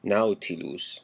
Ääntäminen
Vaihtoehtoiset kirjoitusmuodot nautilos Ääntäminen Classical: IPA: /ˈnau.ti.lus/ Haettu sana löytyi näillä lähdekielillä: latina Käännös Substantiivit 1. paper nautilus 2. argonaut Suku: m .